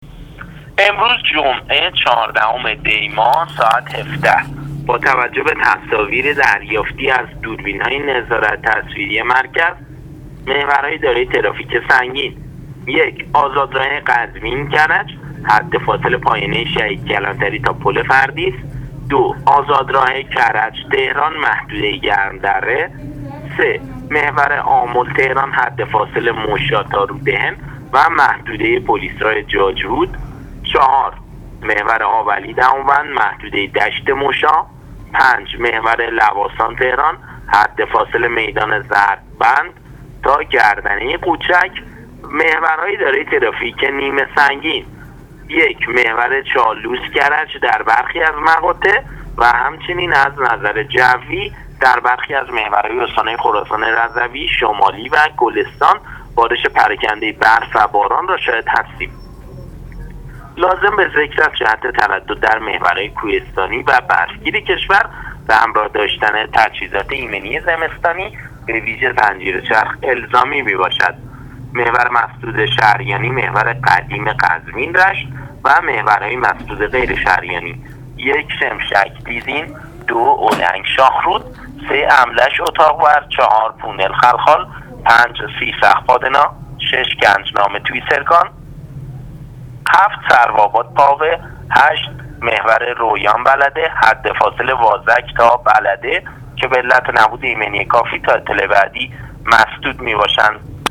آخرین وضعیت محورهای مواصلاتی کشور را از طریق رادیو اینترنتی پایگاه خبری وزارت راه و شهرسازی بشنوید.